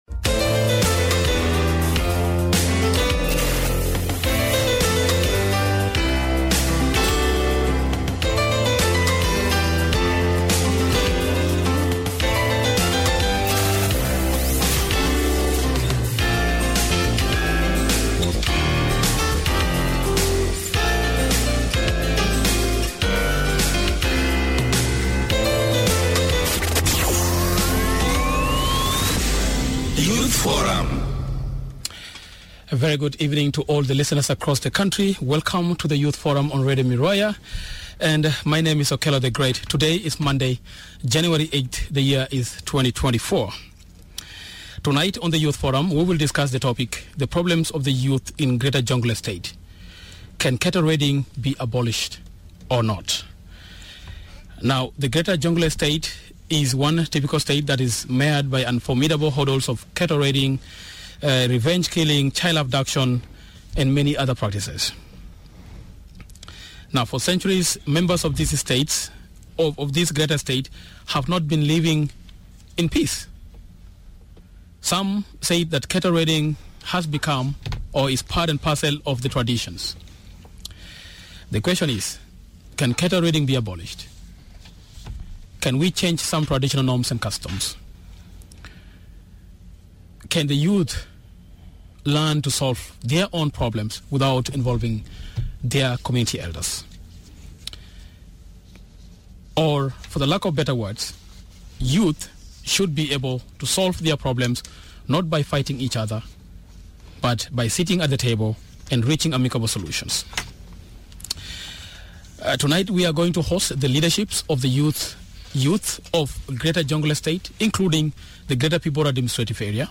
Youth Forum: The problems of youth in Greater Jonglei: ''Can cattle raiding be abolished in the area? ''